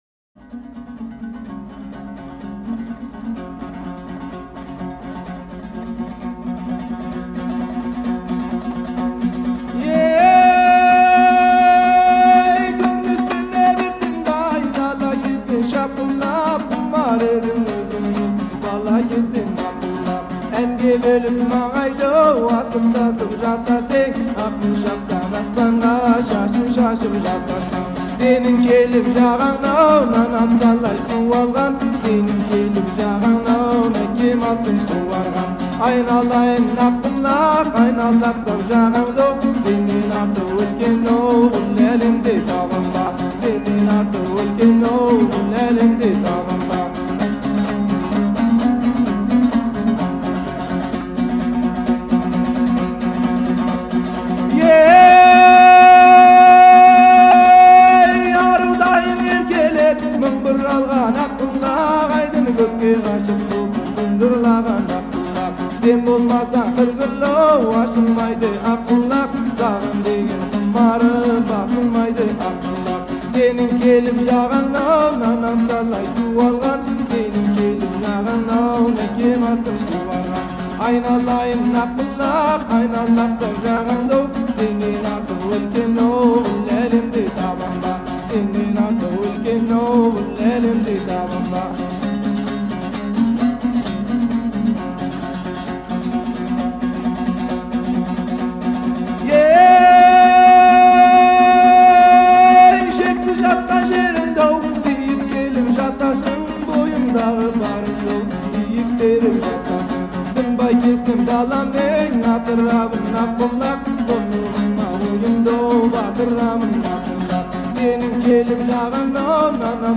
Canción tradicional turcomana